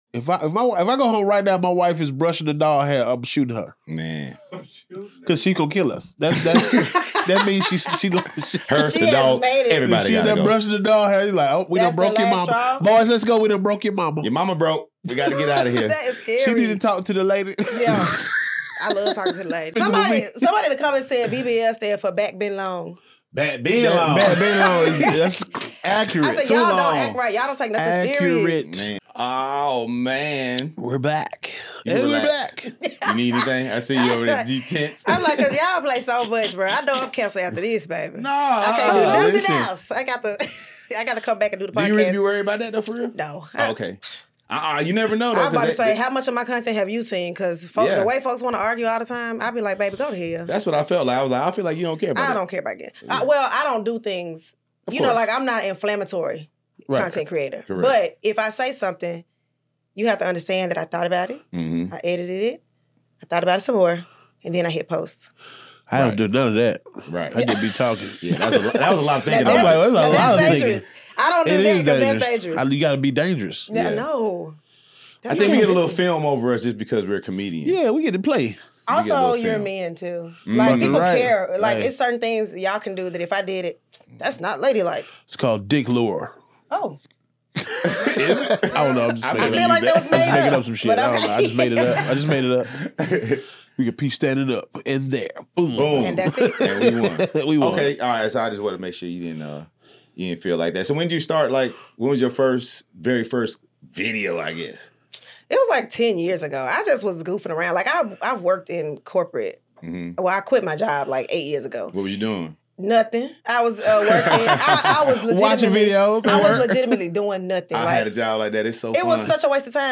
TikTokInterview